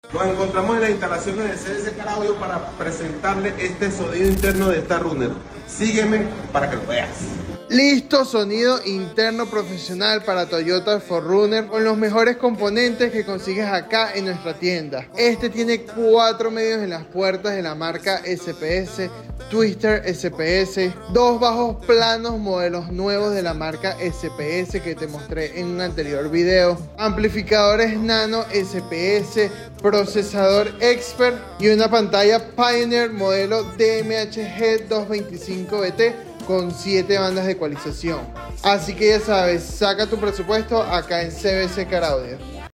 SONIDO INTERNO PARA TOYOTA 4 RUNNER CON LOS MEJORES COMPONENTES QUE CONSIGUES EN NUESTRA TIENDA